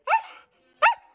dog
bark_27027.wav